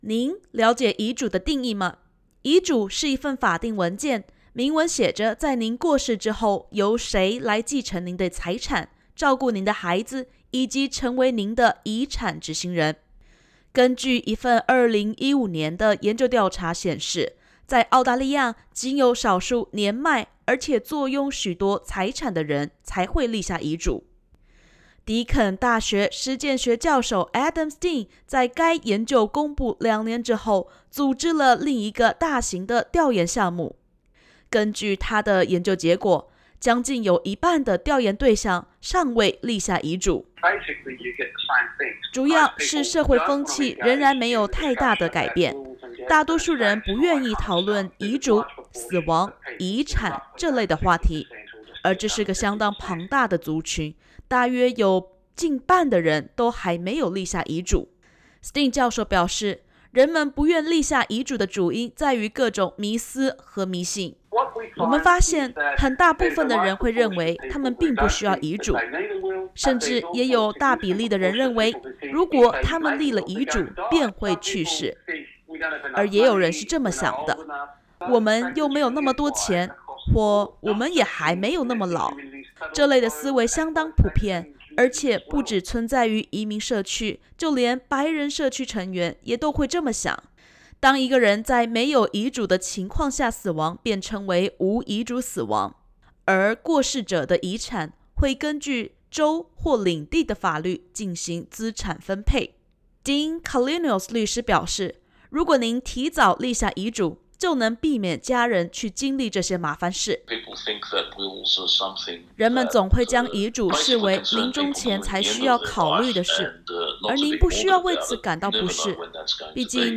（点击首图收听完整采访音频）